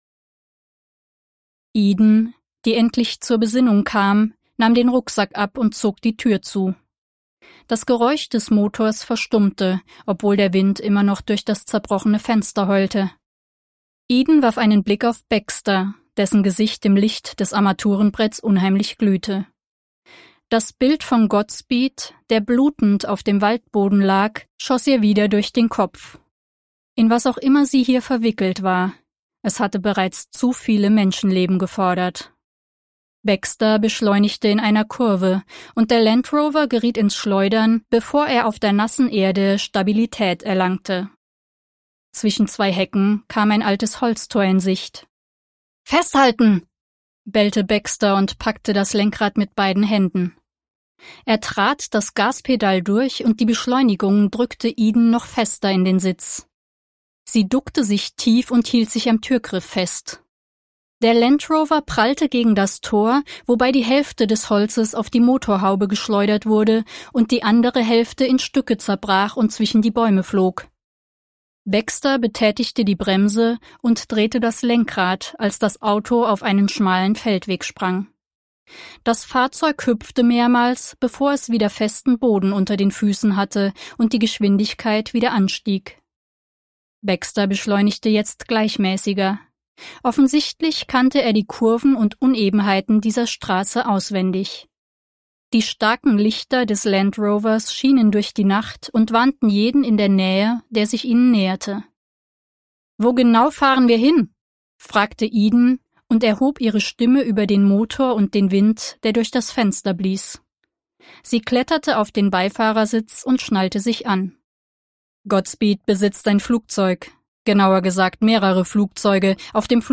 • Top-Studioqualität und professioneller Schnitt
Hörbuch